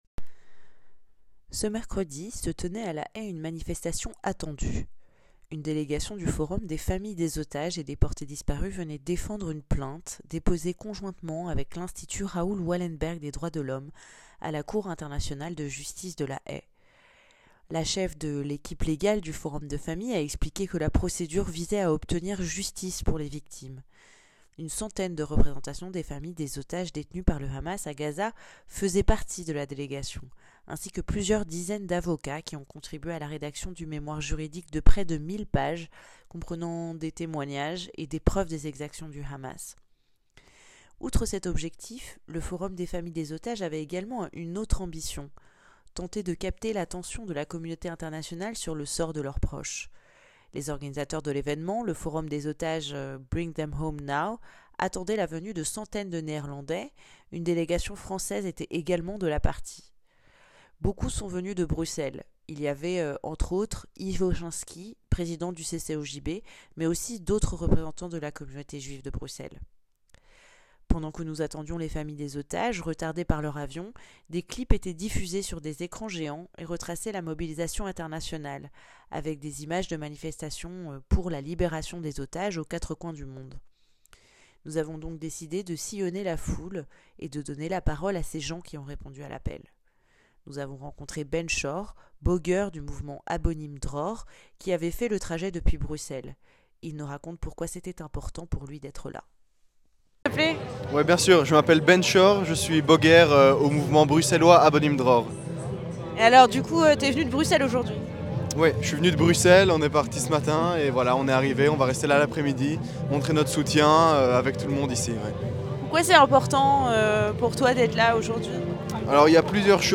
L'interview communautaire depuis La Haye (16/02/24)